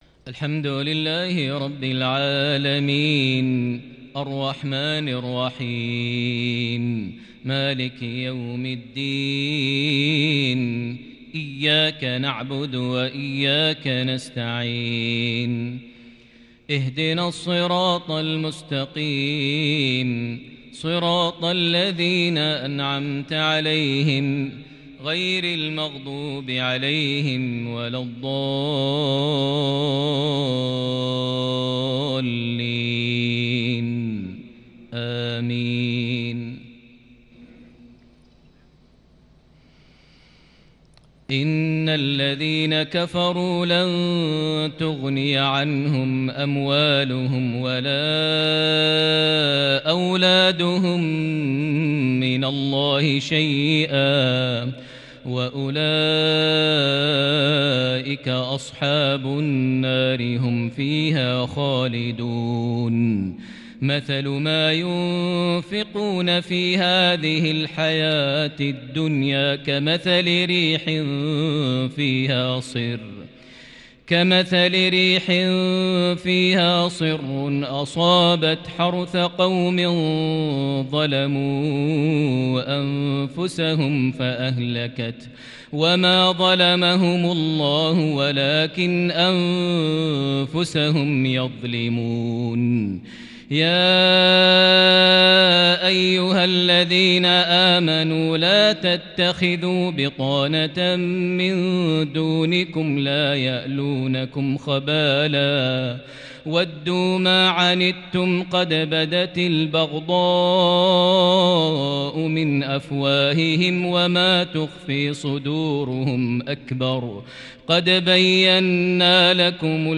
تحبير قرآني بديع لعشائية مميزة من سورة آل عمران (116-129) | 25 ربيع الأول 1442هـ > 1442 هـ > الفروض - تلاوات ماهر المعيقلي